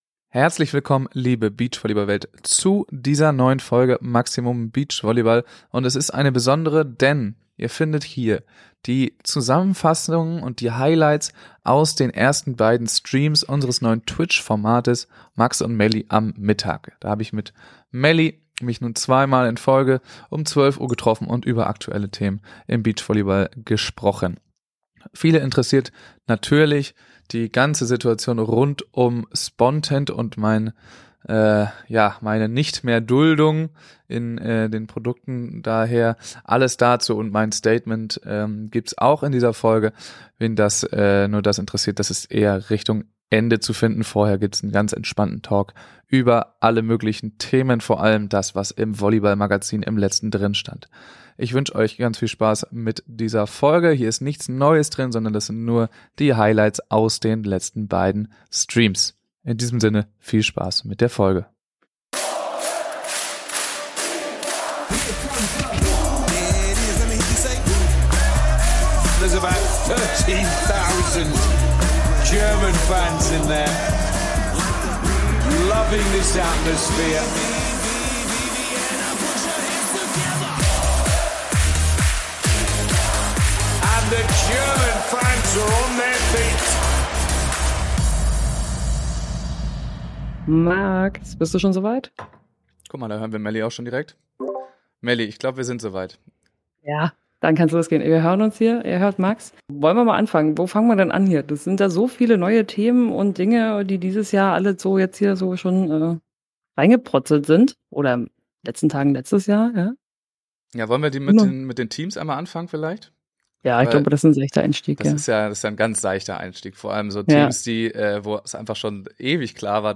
Eine lockerer Talk in der Mittagspause über alles was die Beachvolleyballwelt hergibt. Dies ist die Zusammenfassung der Highlights der ersten beiden Folgen MuMaM!